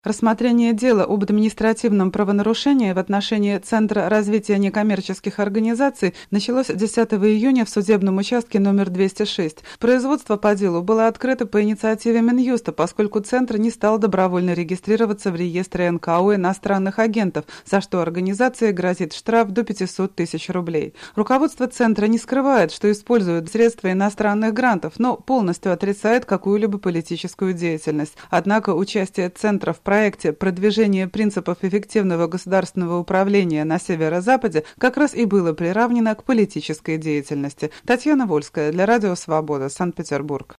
Из Петербурга передает корреспондент Радио Свобода